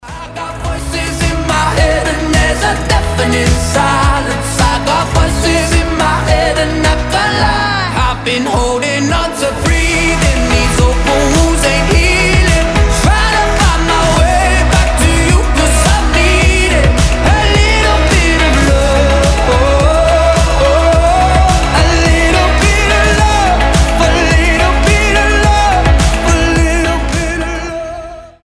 • Качество: 320, Stereo
красивый мужской голос
indie pop
alternative